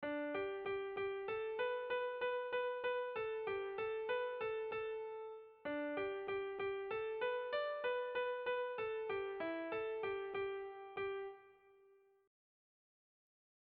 Bertso melodies - View details   To know more about this section
Erlijiozkoa
Lau puntuko berdina, 8 silabaz
AB